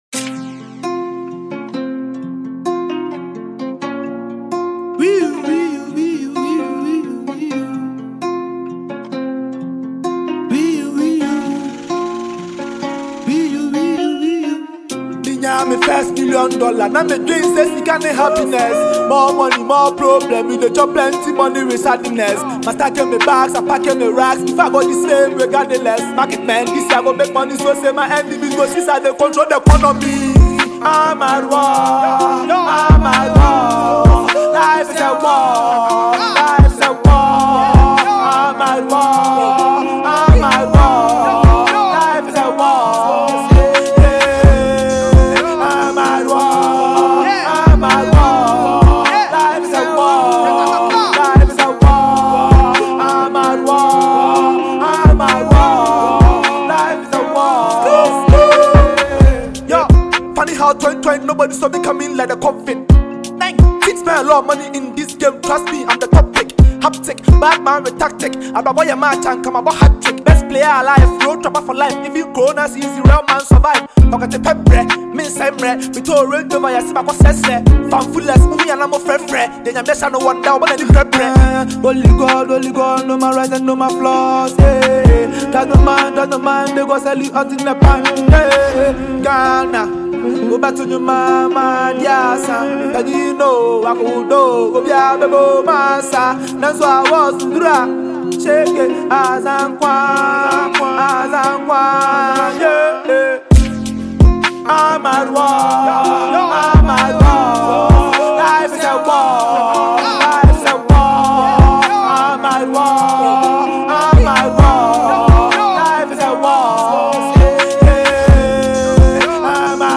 Ghana Music Music
rapper
heavy record